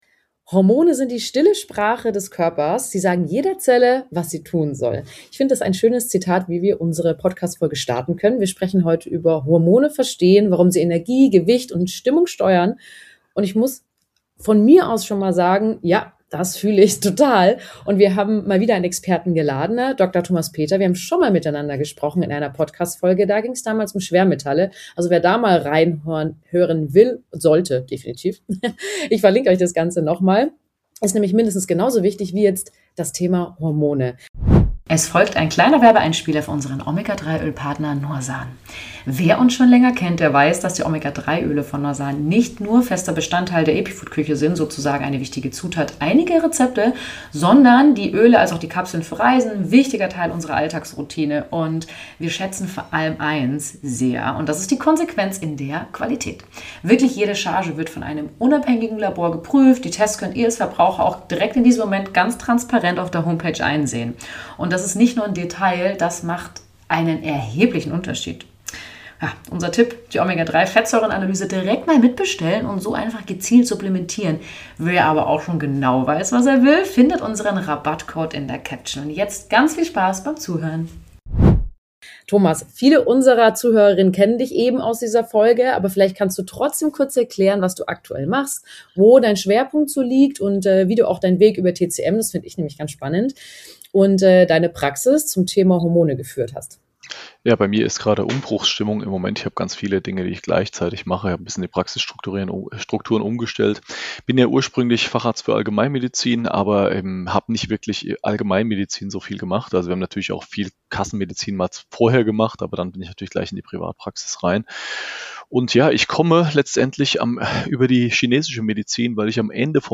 Experteninterview